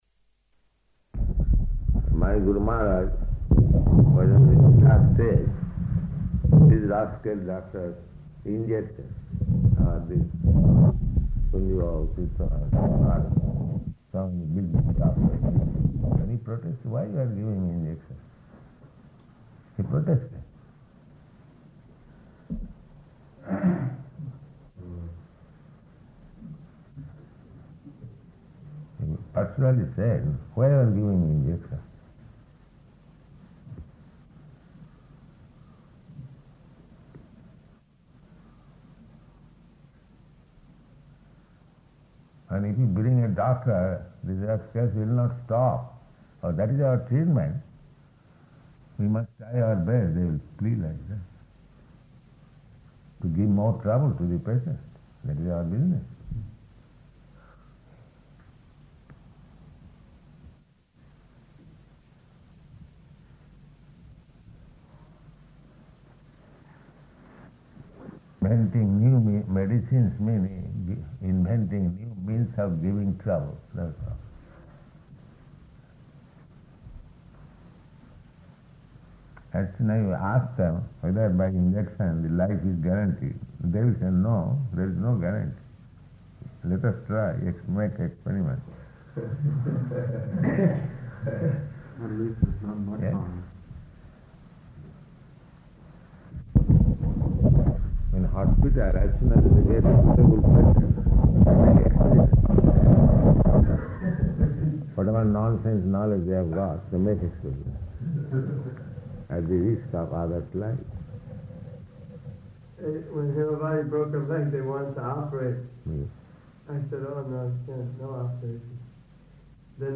Room Conversation
Room Conversation --:-- --:-- Type: Conversation Dated: February 14th 1971 Location: Gorakphur Audio file: 710214R1-GORAKPHUR.mp3 Prabhupāda: ...my Guru Mahārāja was in his last days, these rascal doctors injected...